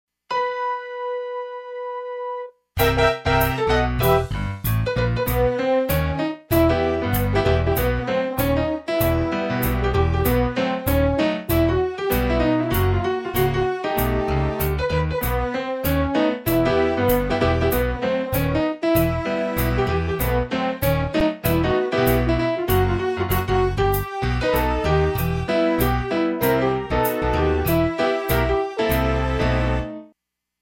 Instrumental Only